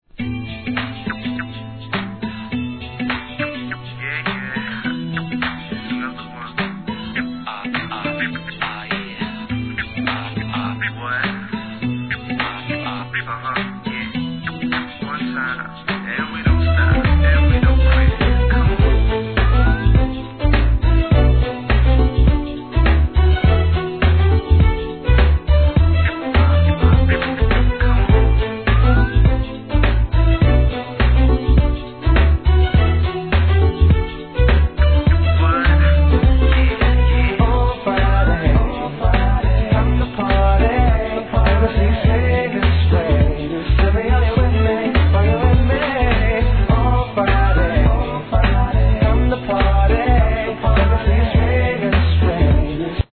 HIP HOP/R&B
アコースティックの哀愁ナンバー!